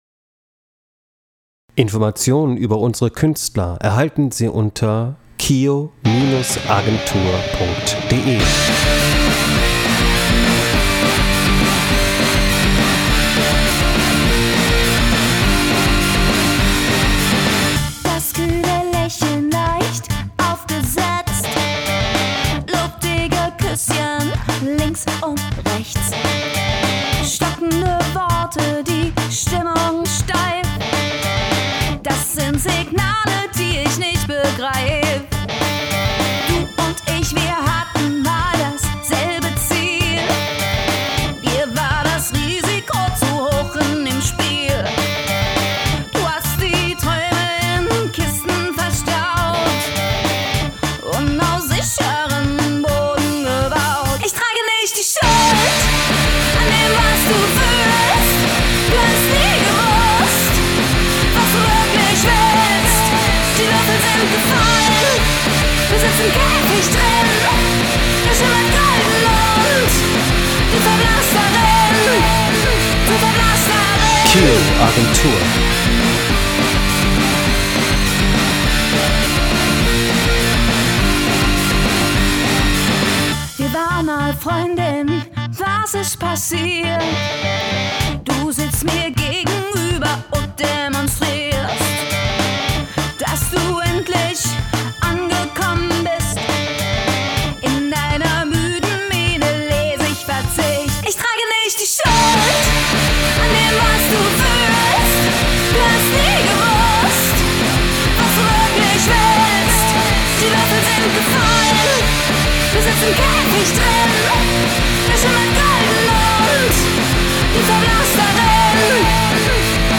rotzige Sythiesounds und Melodien